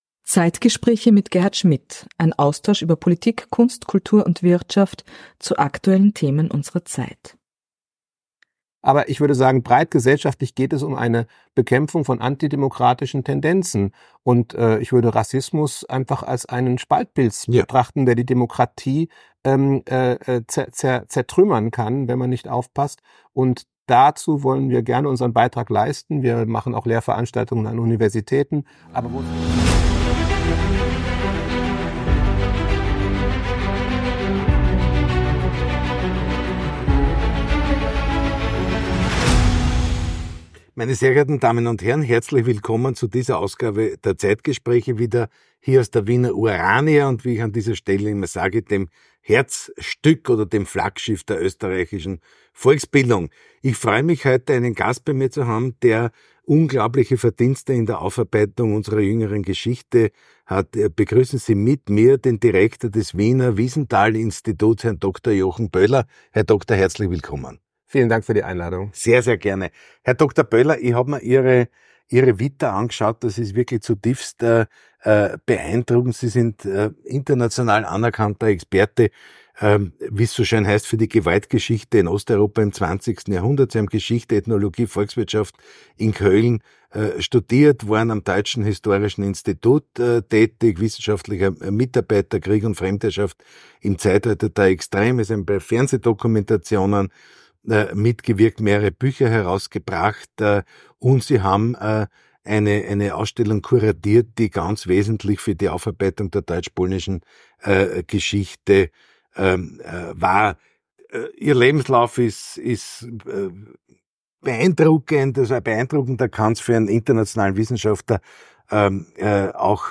In diesem Interview